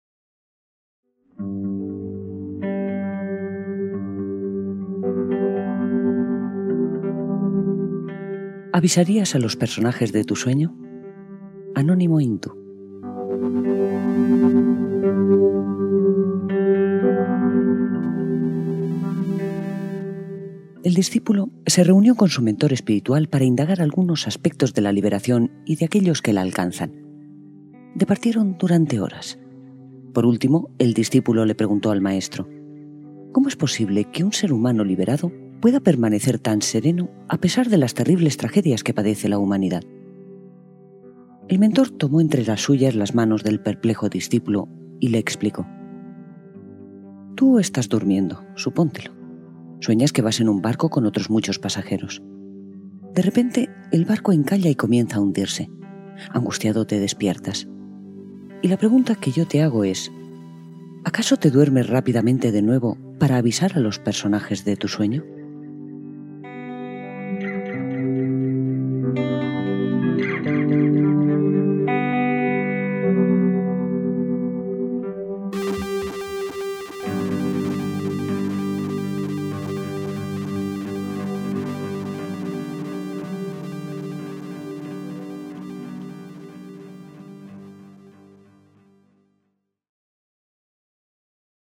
Audiolibro: ¿Avisarías a los personajes de tu sueño?
Cuento oriental hindú
Música: Gelosoft